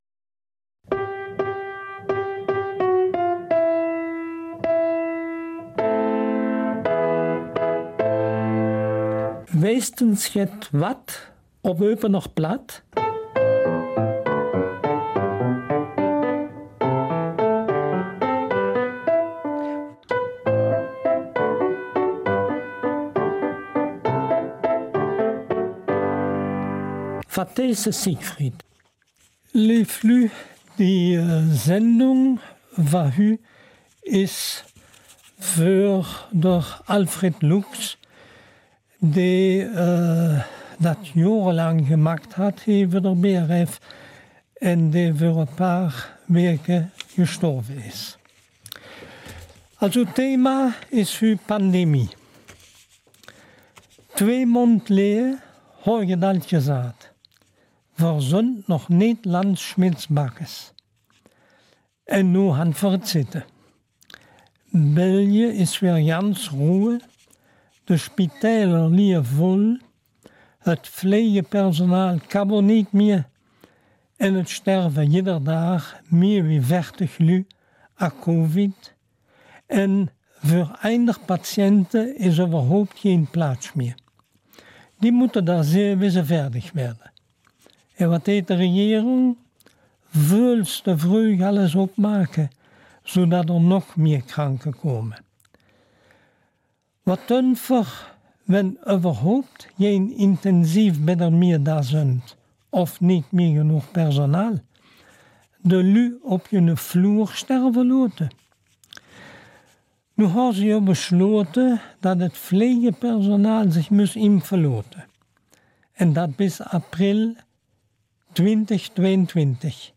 Eupener Mundart - 12.